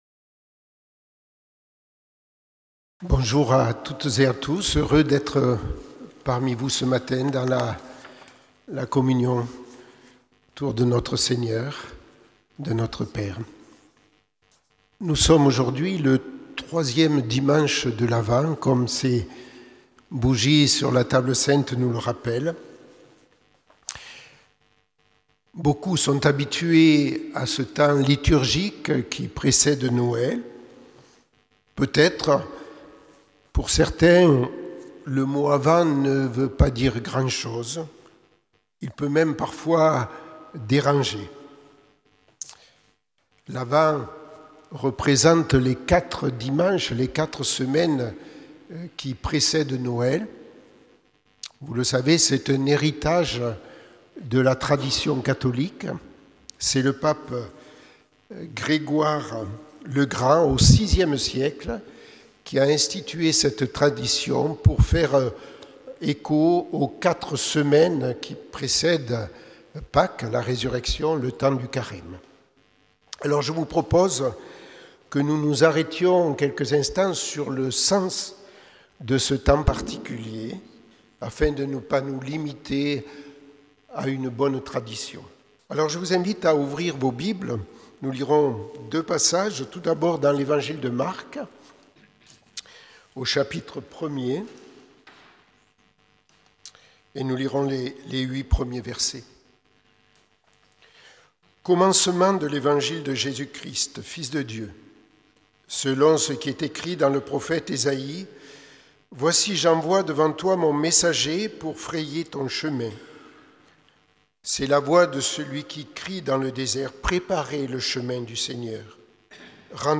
Prédication du 15 décembre 2024.